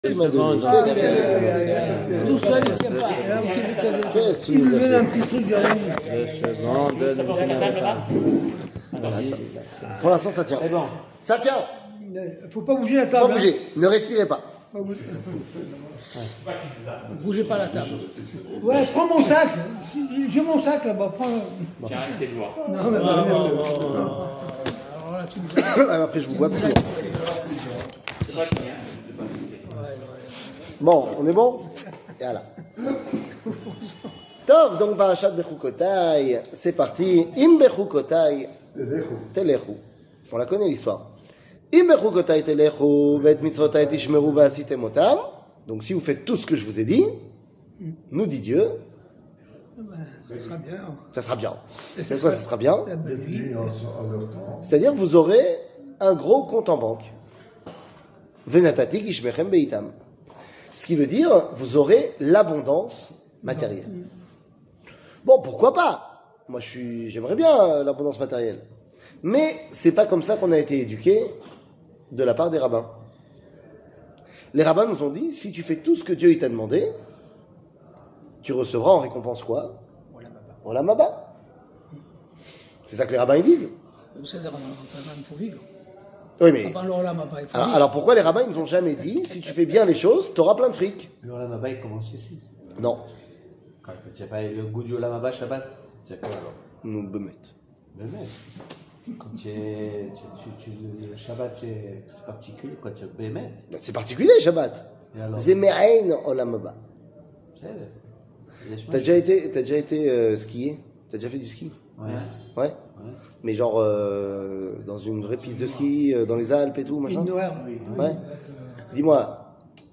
Parachat Behoukotai, L'importance de ce monde 00:40:07 Parachat Behoukotai, L'importance de ce monde שיעור מ 17 מאי 2022 40MIN הורדה בקובץ אודיו MP3 (36.71 Mo) הורדה בקובץ וידאו MP4 (88.64 Mo) TAGS : שיעורים קצרים